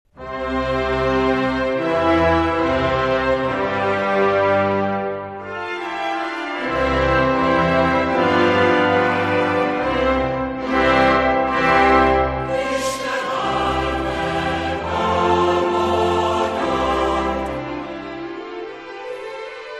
Ennek az évnyitónak méltó folytatása hangzott el - a felvételünkön szereplő kórus közreműködésével - még abban a hónapban a budavári Mátyás-templomban a Magyar Kultúra Napjának estéjén.
Népszh. 1344) egy négyszólamú vegyeskar, zenekarral.